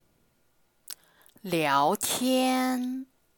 Người miền bắc Trung Quốc có thể nói tốt, nhưng người miền nam sẽ phát âm rất khó khăn.
• Người miền nam phát âm 儿 (er)